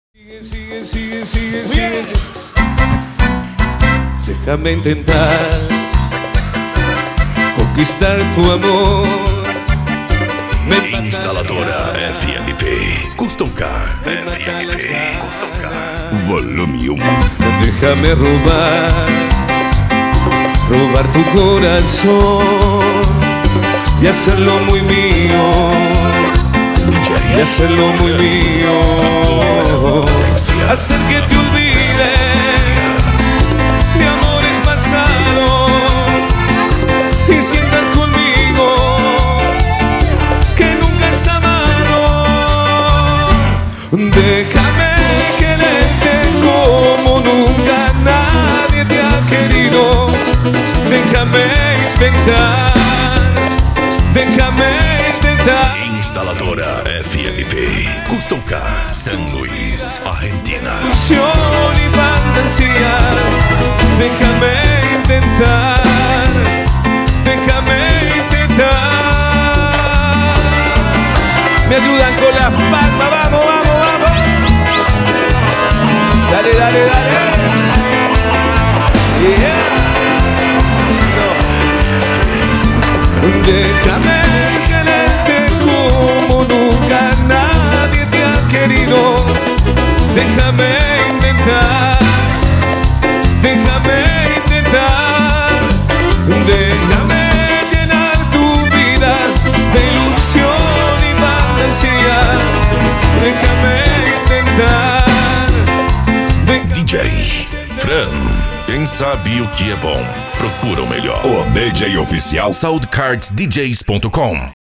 Bass
Cumbia
Electro House
PANCADÃO
Psy Trance
Remix